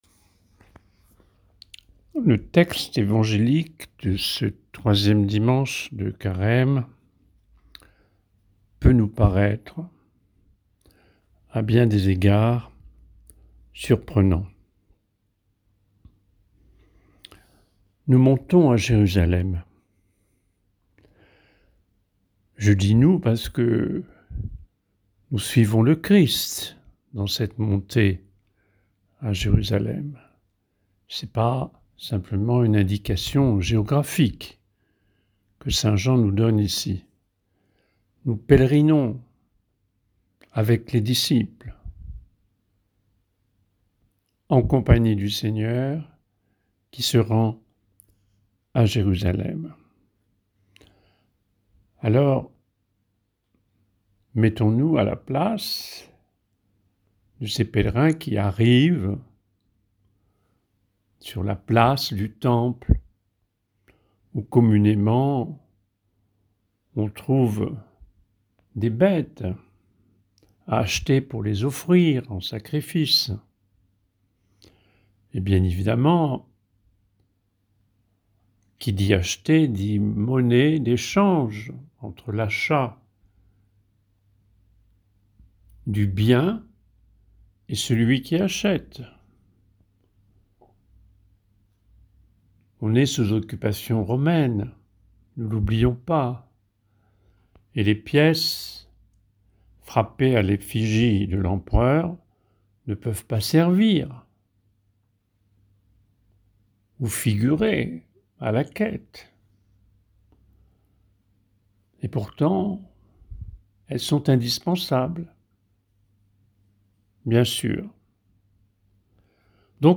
Homélie